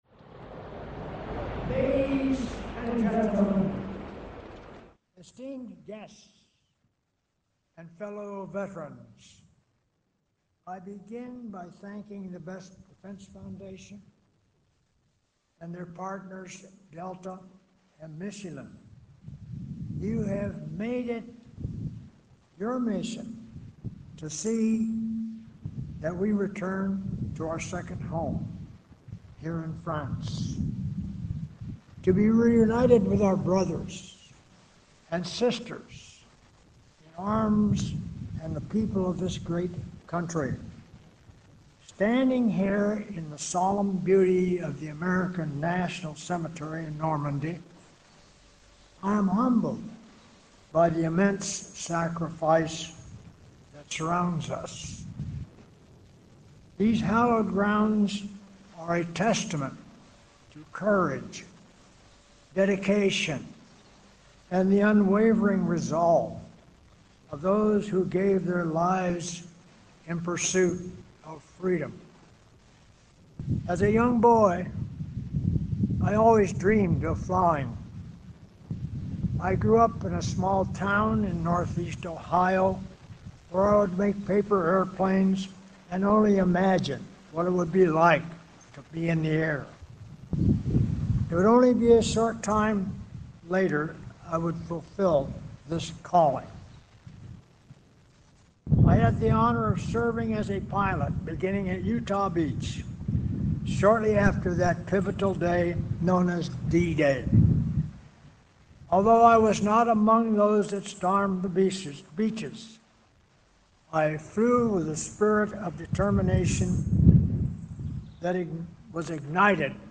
Address at the 81st D-Day Landings Anniversary at Normandy American Cemetery
delivered 6 June 2025, Colleville-sur-Mer, France
Audio Note: AR-XE = American Rhetoric Extreme Enhancement